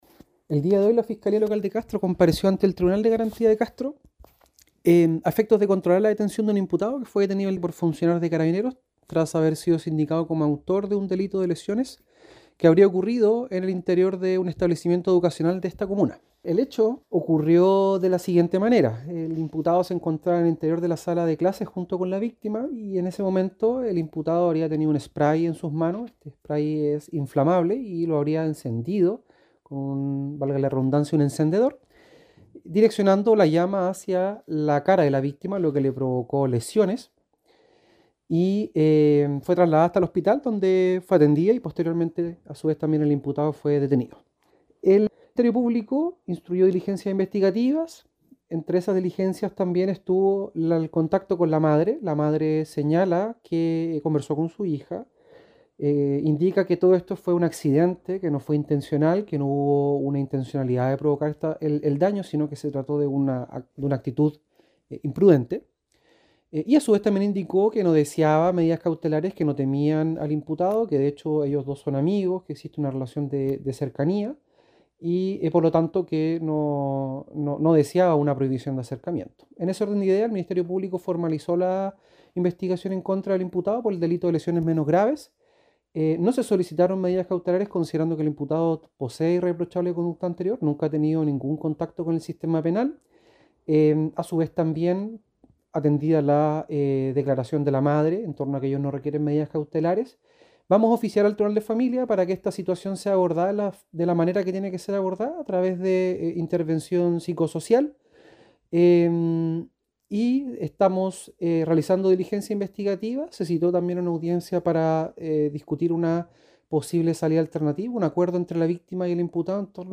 El fiscal David Parrini dijo que no se impusieron medidas cautelares al joven que causó este suceso.